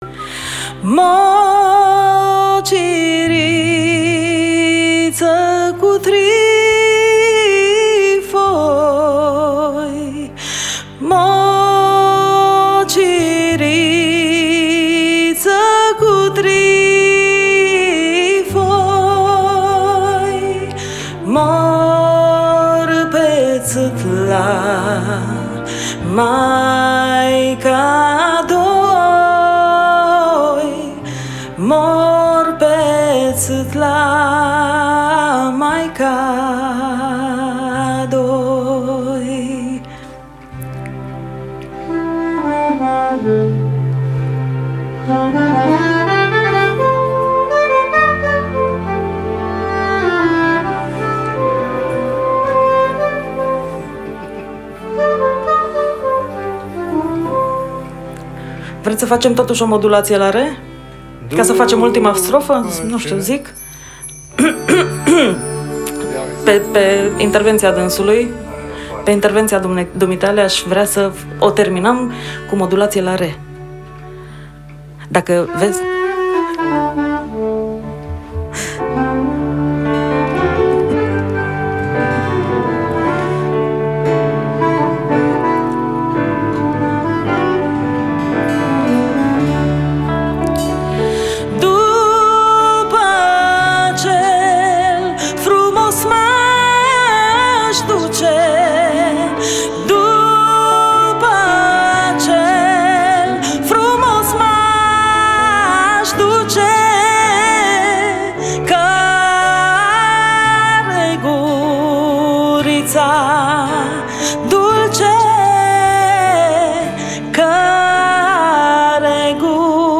Pian
Chitara
Percutie